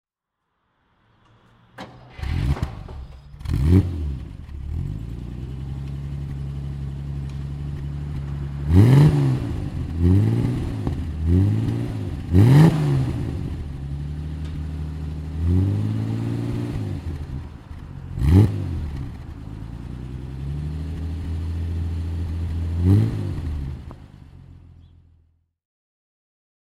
With 55 hp from a displacement of two liters, the BMW 327 was a sports car designed for fast and comfortable travel.
Eighty years later, we see things somewhat differently, but the BMW 327 with its straight-six engine has lost hardly any of its appeal.
BMW 327 Cabriolet (1940) - Starten und Leerlauf